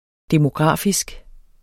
Udtale [ demoˈgʁɑˀfisg ]